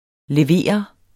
Udtale [ leˈveˀʌ ]